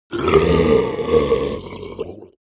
wolf_die.wav